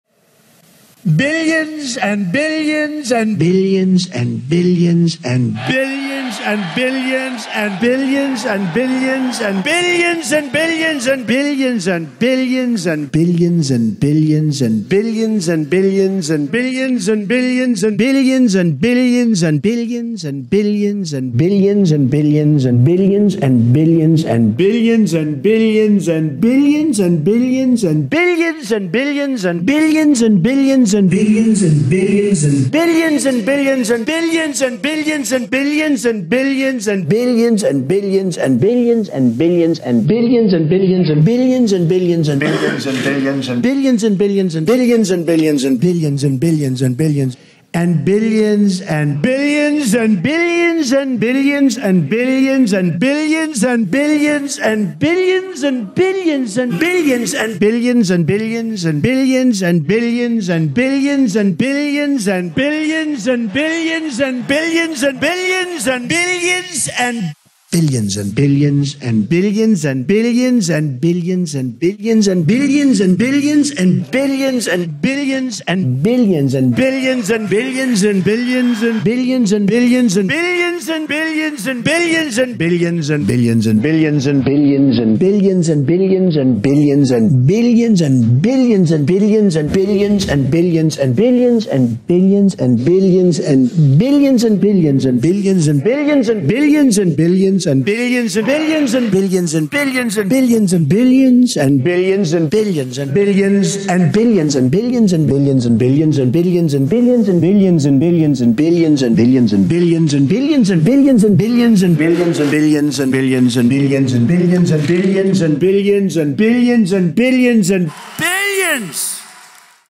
Donald Trump Says Billions And Billions And Billions-u_aLESDql1U.f140.m4a